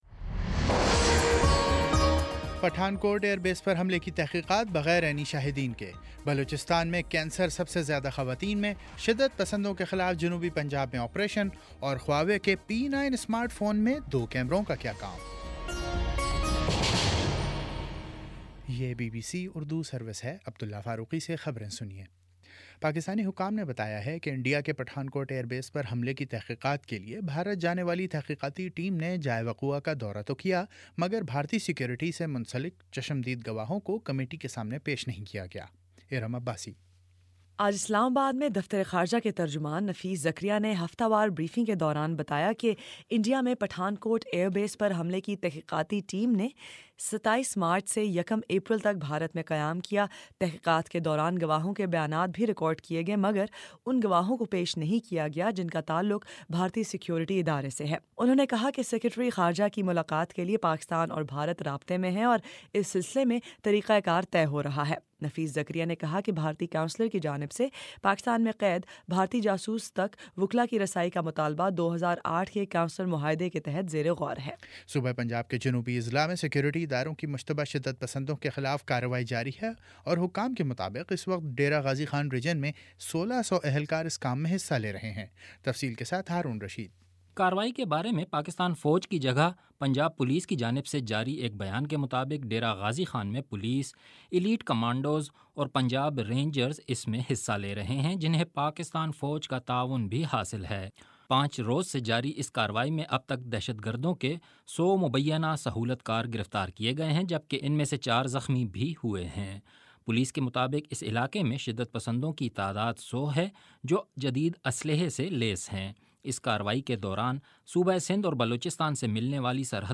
اپریل 07: شام سات بجے کا نیوز بُلیٹن